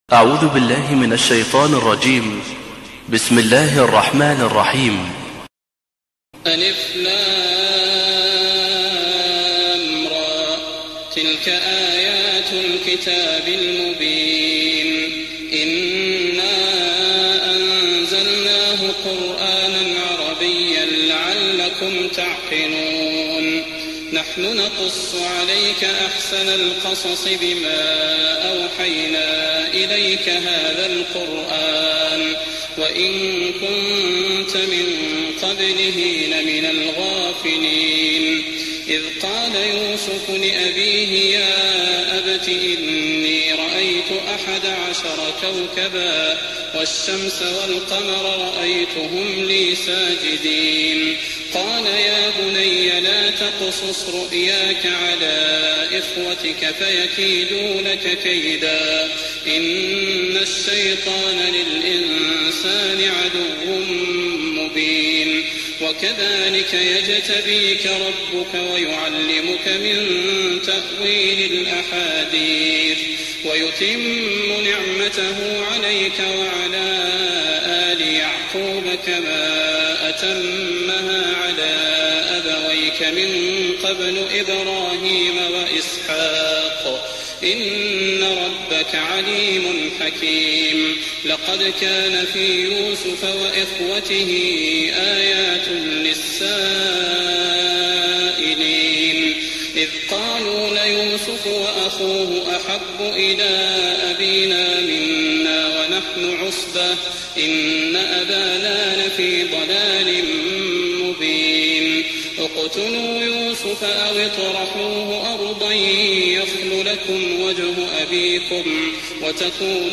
تهجد رمضان 1419هـ من سورة يوسف (1-111) Tahajjud Ramadan 1419H from Surah Yusuf > تراويح الحرم النبوي عام 1419 🕌 > التراويح - تلاوات الحرمين